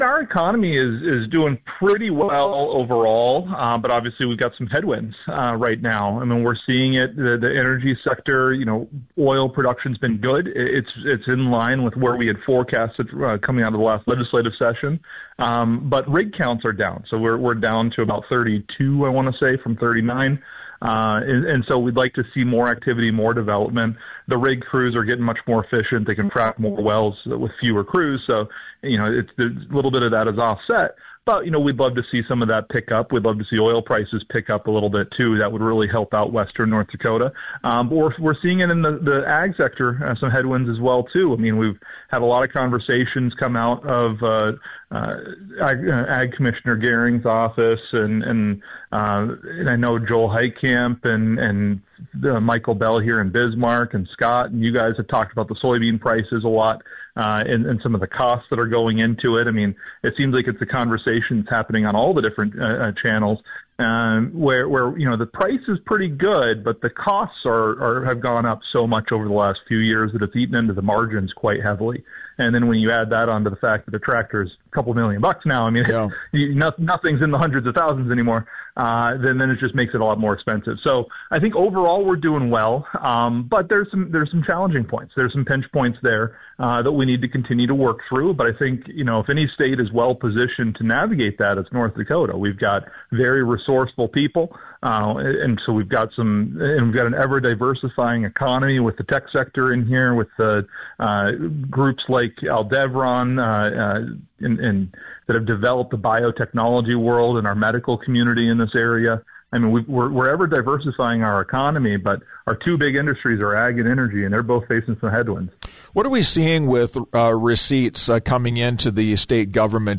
ND Treasurer Tom Beadle
tom-beadle-for-web.mp3